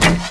troll_archer_bow.wav